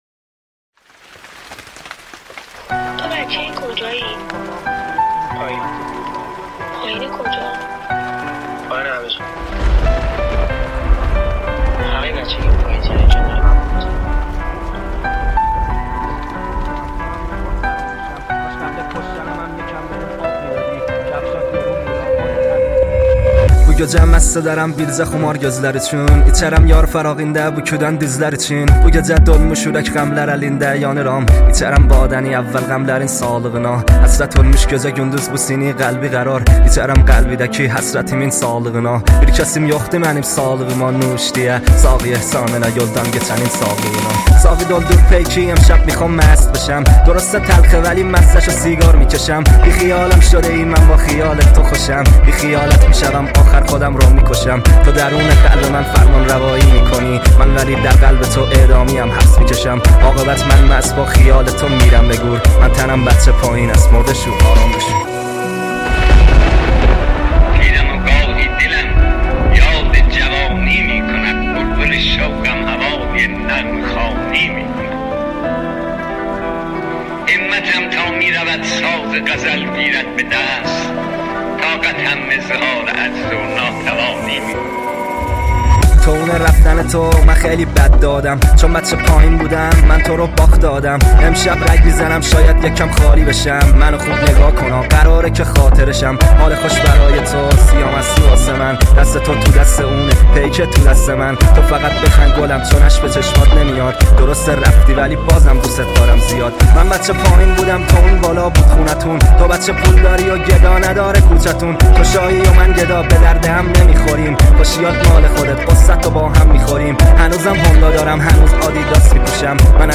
عاشقانه غمگین رپ ریمیکس تند بیس دار
ریمیکس بیس دار